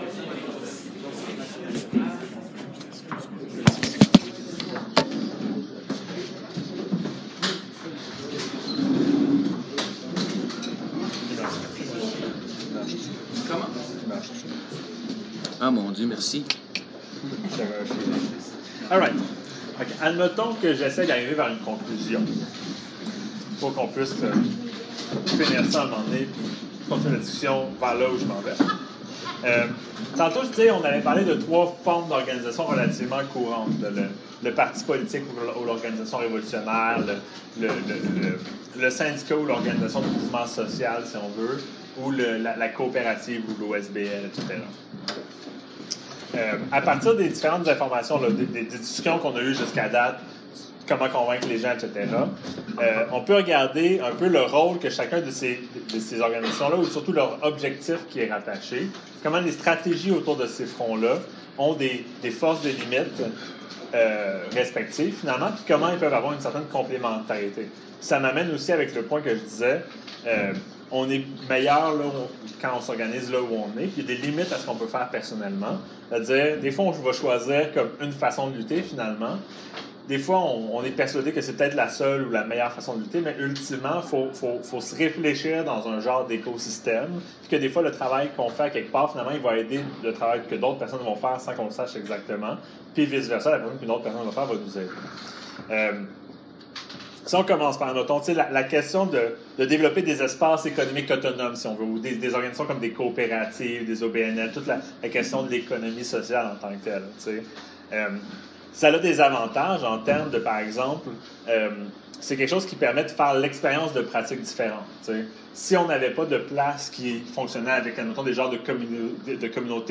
Enregistrement de la présentation